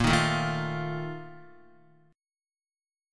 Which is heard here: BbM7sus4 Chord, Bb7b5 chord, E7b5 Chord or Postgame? BbM7sus4 Chord